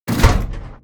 gun-turret-deactivate-04.ogg